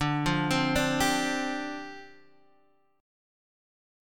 Dm11 chord